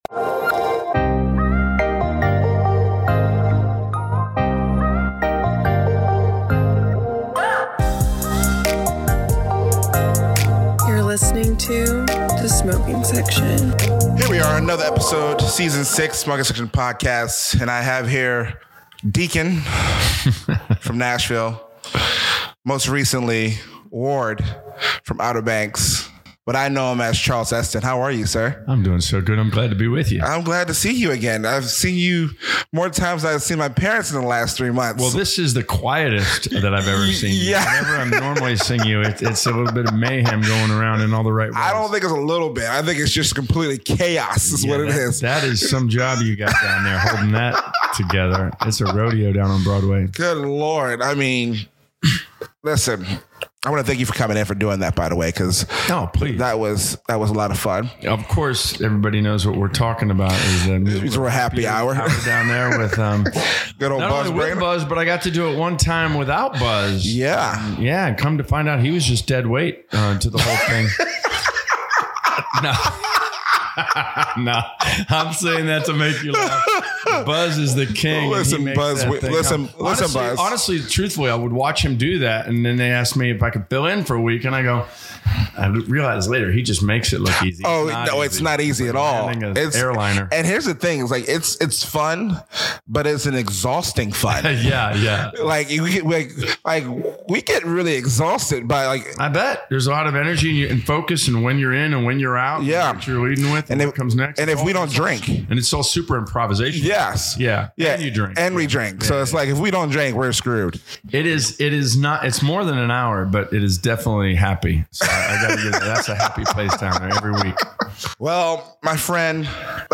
Smoking Section centers around conversations with various music industry professionals, from all avenues, as well as business owners, discussing their roads to success, in hopes of educating and inspiring others to follow in their footsteps -- All while having a cigar and drink.